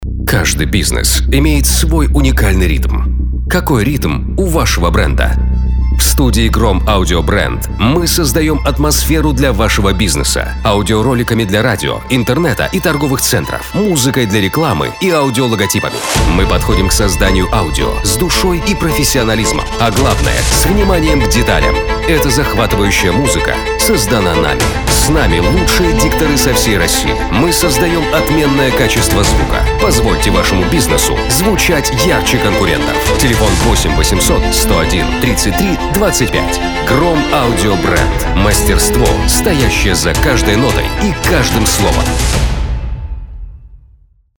Голос мужской 2
Аудиореклама. Формат ролика - имиджевый. Мужской голос - доверительный, вдохновляющий.